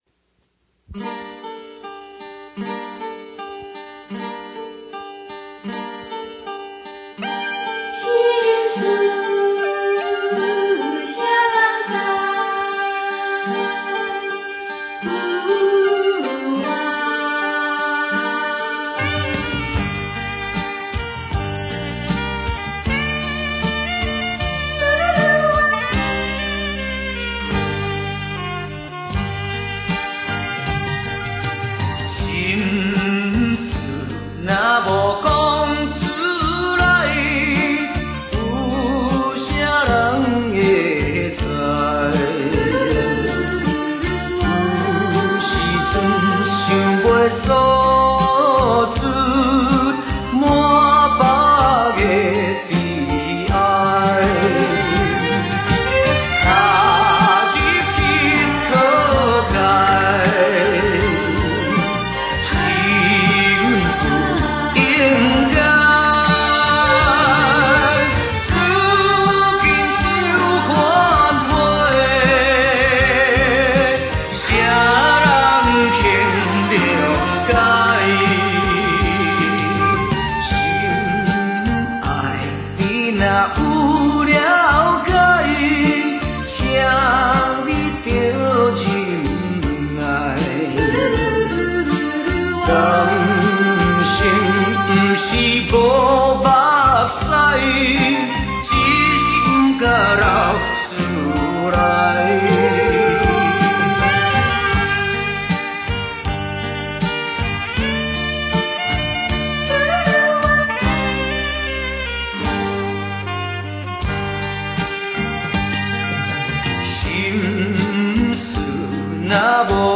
MP3華語流行樂合輯DVD版